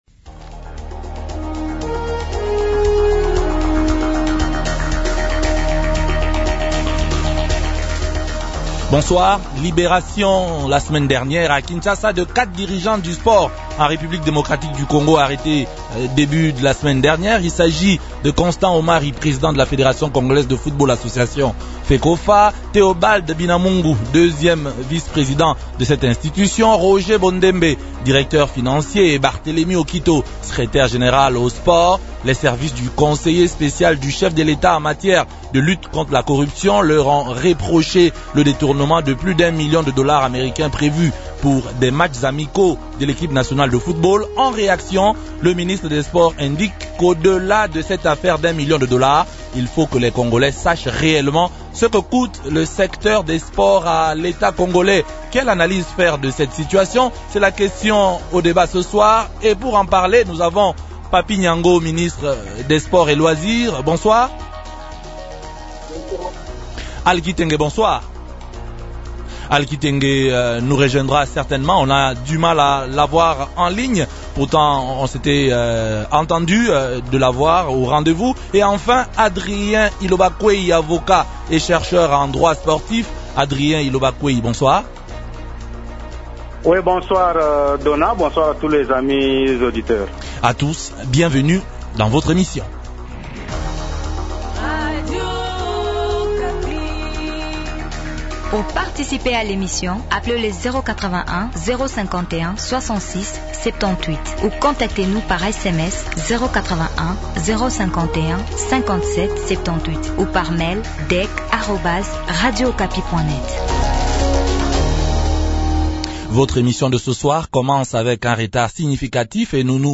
-Quelle analyse faire de cette affaire ? Invités Papy Nyango, Ministre des sports et loisirs.
Analyste économique et stratège.
Avocat et chercheur en droit sportif.